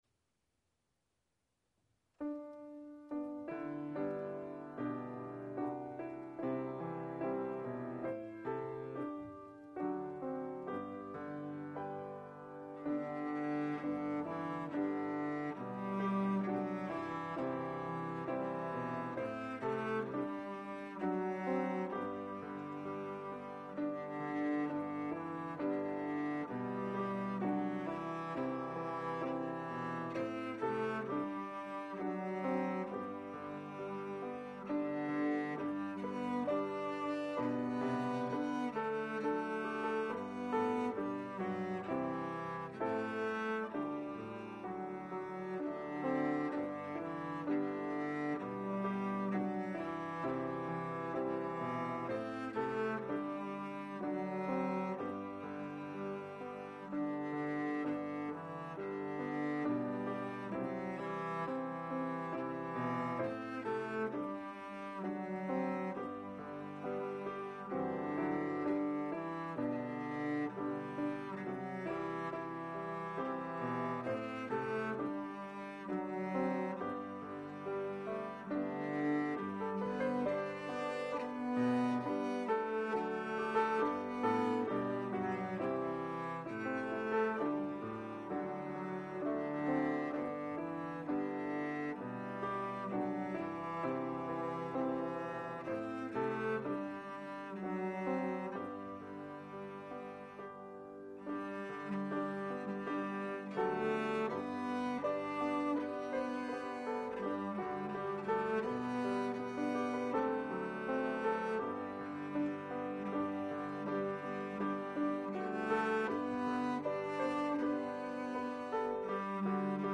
hymns on cello and piano